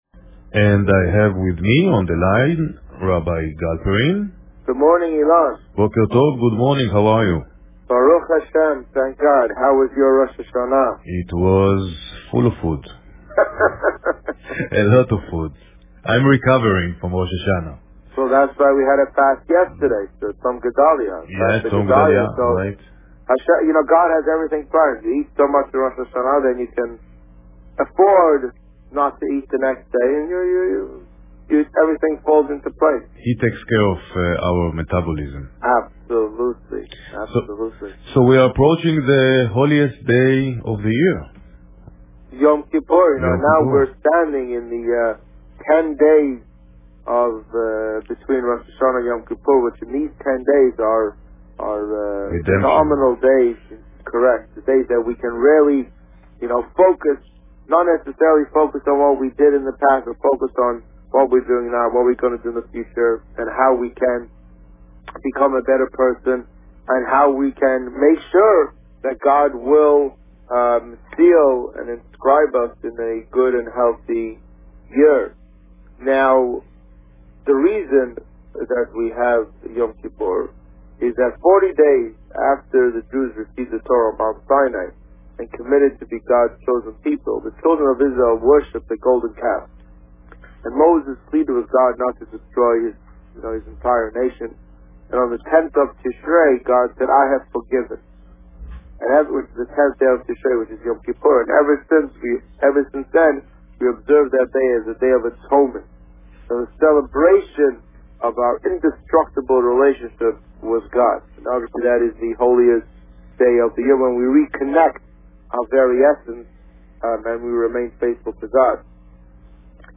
As a result, the various "Rabbi on Radio" broadcasts have not been made available on a timely basis.
Here now, are the recorded interviews from the month of September: